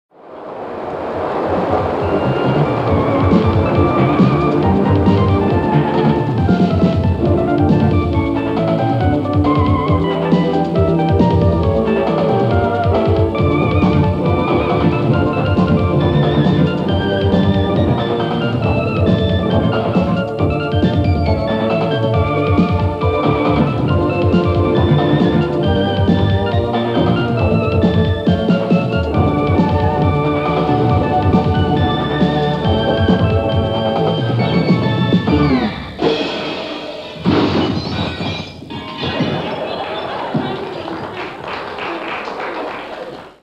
Ending theme (audio) .wma 705 KB